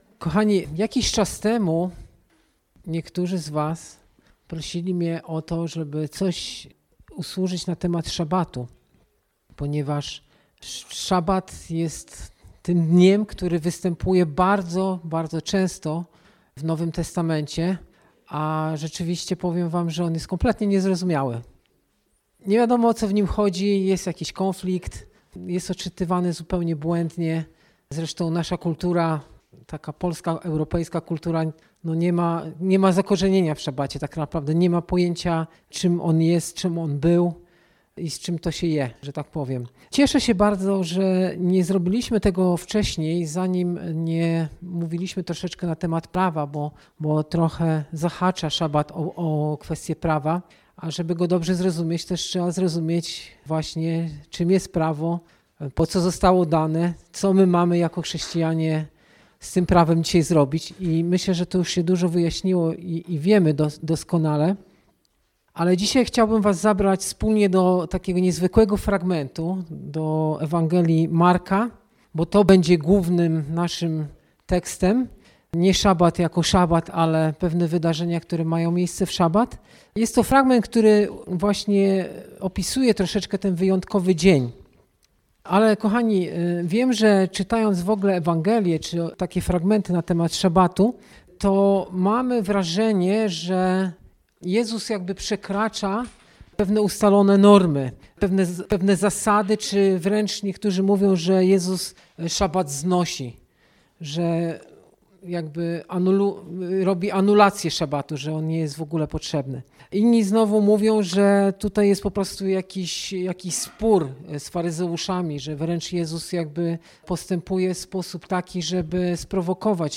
Posłuchaj kazań wygłoszonych w Zborze Słowo Życia w Olsztynie.